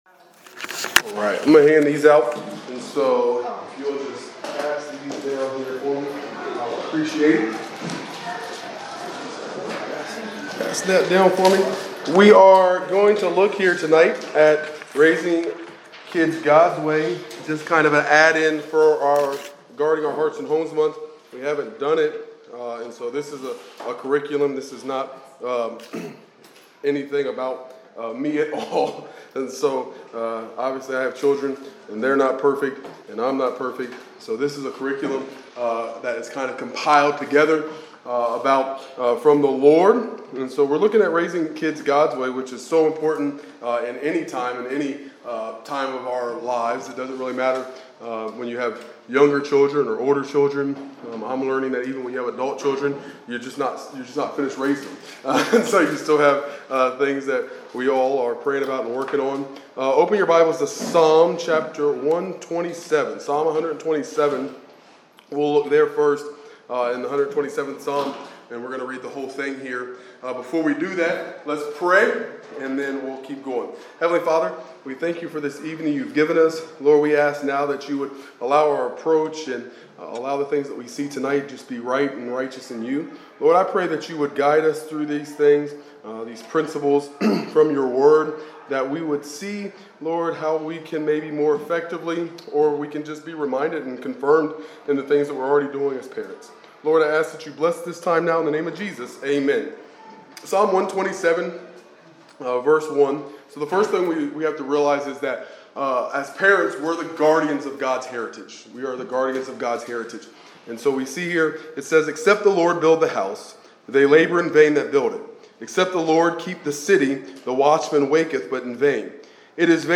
Parenting Class